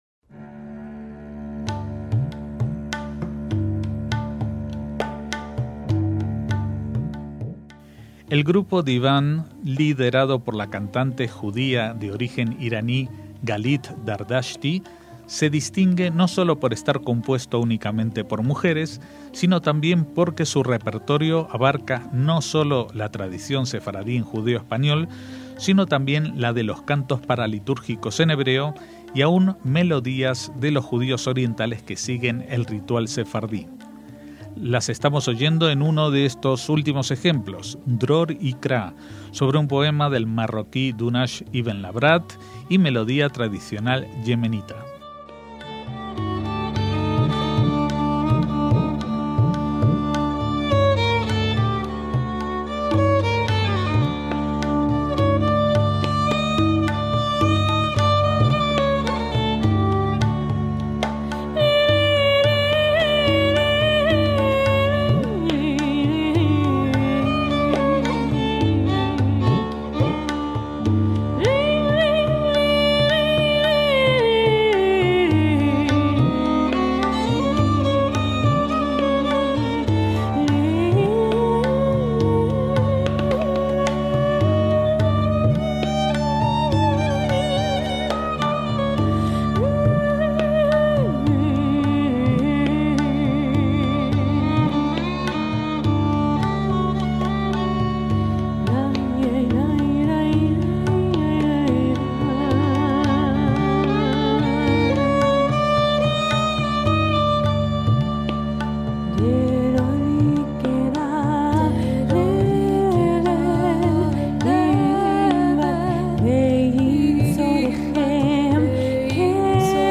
MÚSICA SEFARDÍ
tabla hindú